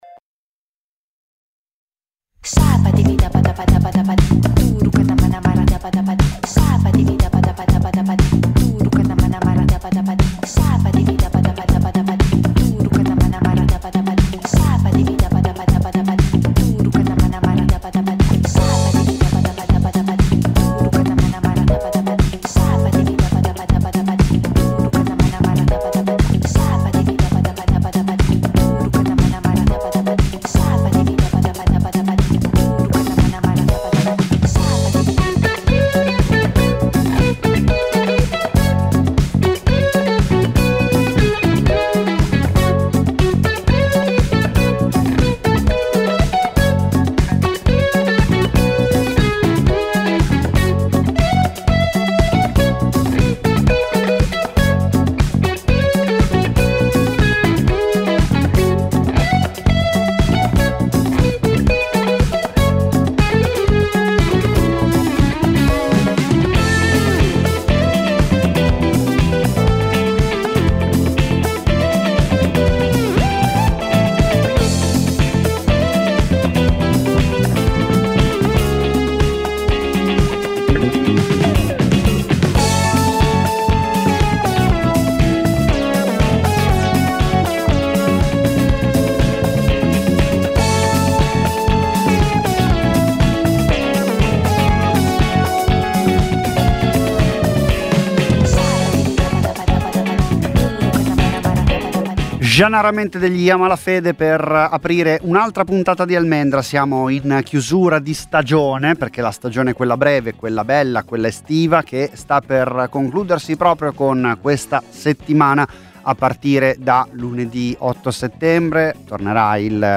Almendra è una trasmissione estiva di Radio Popolare in cui ascoltare tanta bella musica, storie e racconti da Milano e dal mondo, e anche qualche approfondimento (senza esagerare, promesso).